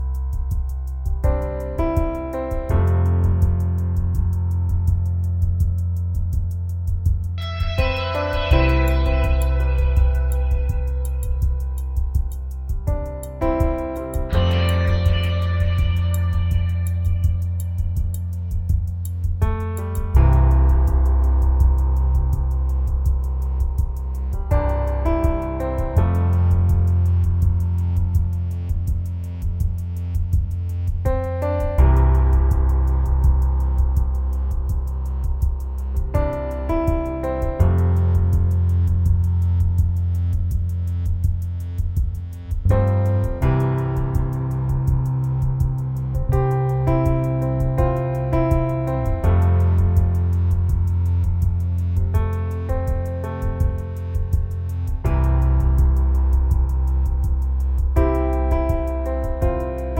Minus Main Guitar For Guitarists 5:25 Buy £1.50